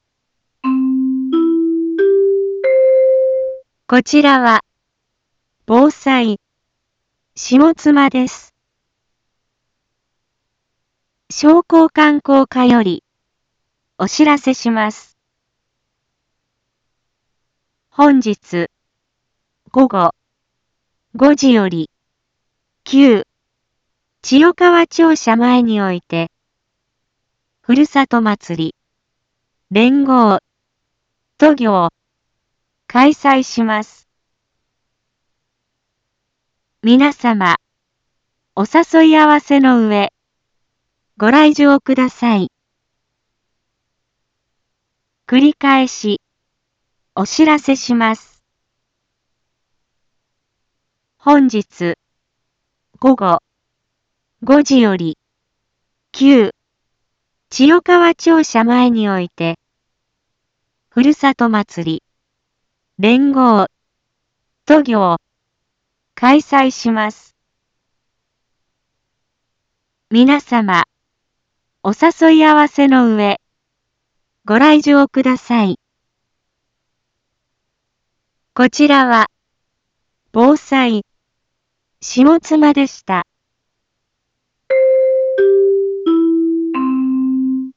一般放送情報
Back Home 一般放送情報 音声放送 再生 一般放送情報 登録日時：2023-07-29 13:01:31 タイトル：「ふるさとまつり連合渡御」のお知らせ インフォメーション：こちらは、防災、下妻です。